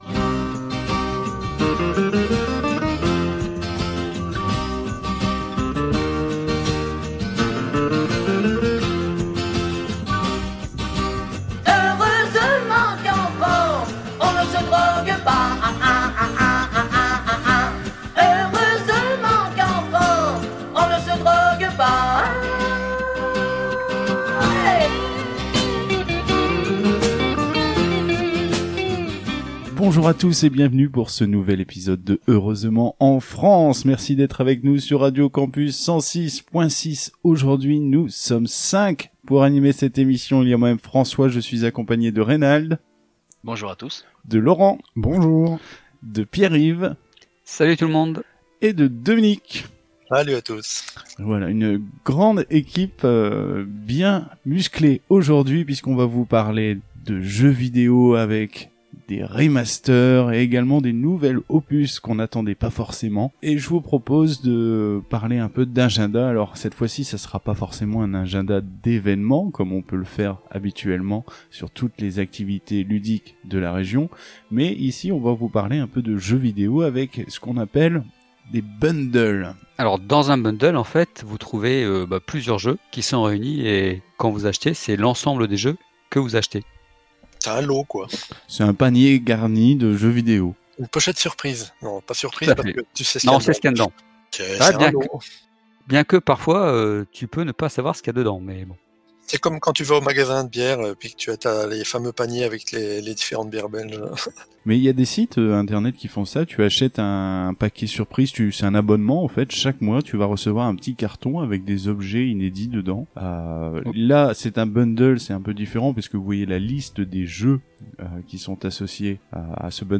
Au sommaire de cet épisode diffusé le 17 mai 2020 sur Radio Campus 106.6 :